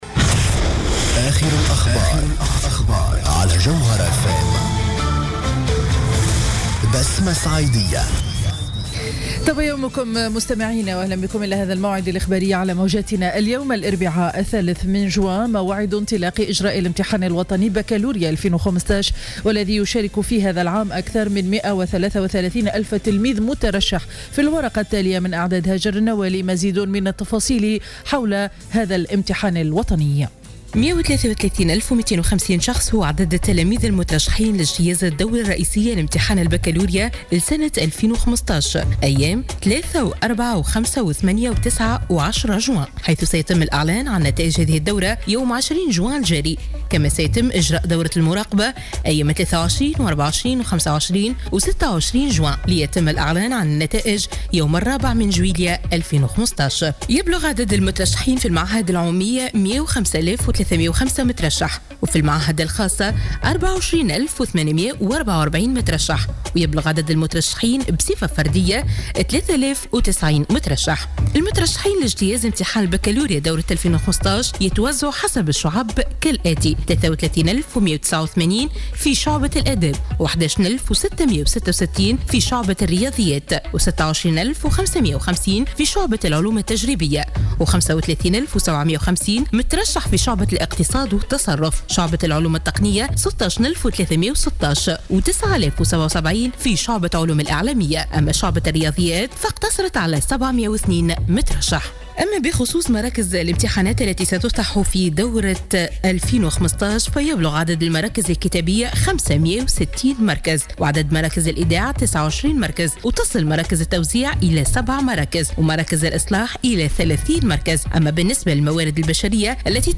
نشرة أخبار السابعة صباحا ليوم الإربعاء 03 جوان 2015